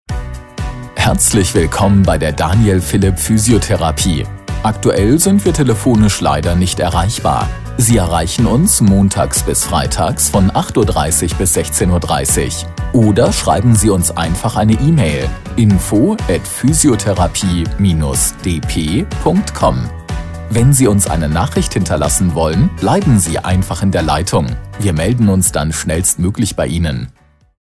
Physiotherapie Telefonansage
AB Ansage Physiotherapie